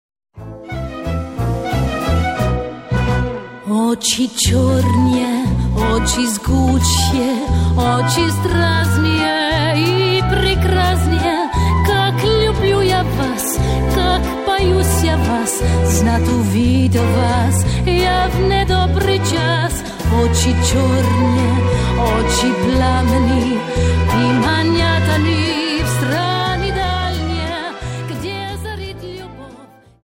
Dance: Viennese Waltz 59